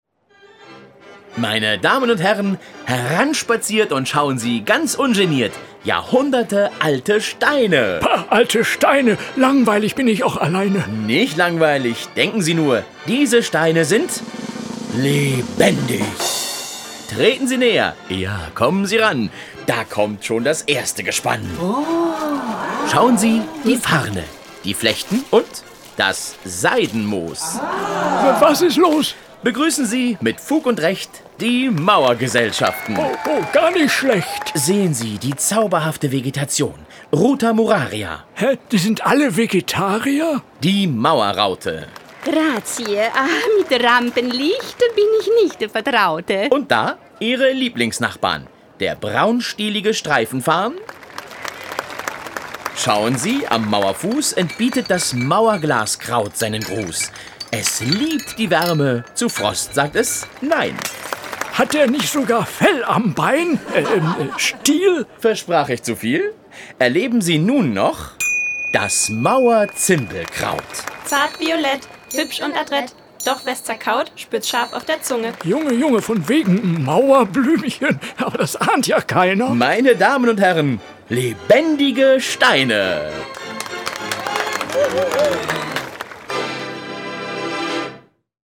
Die Audio-Station bietet acht lustige und unterhaltsame Hörgeschichten für Kinder und Erwachsene.
Hörgeschichten zur Zonser Stadtmauer: